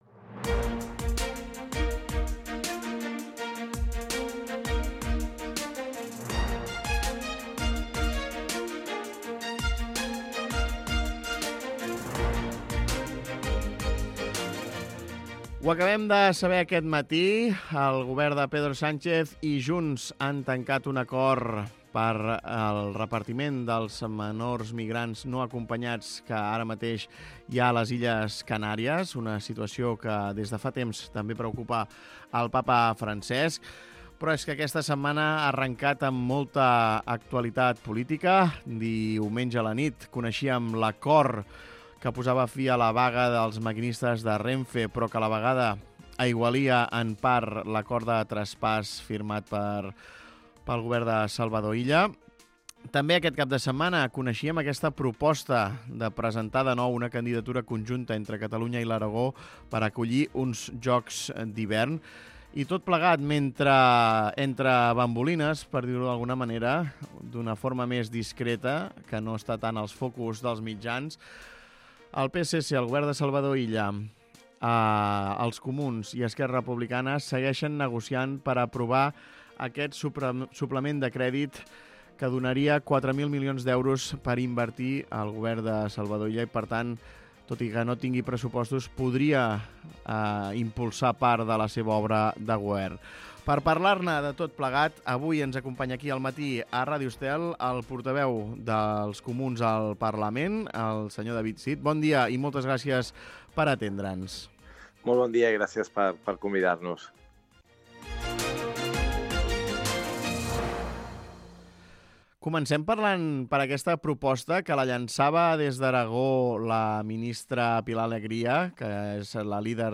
Entrevista a David Cid, portaveu dels Comuns